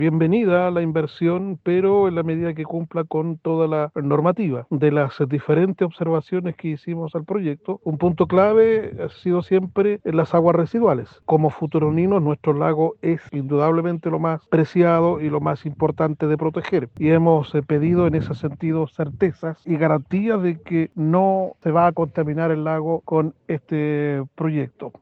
alcalde-futrono.mp3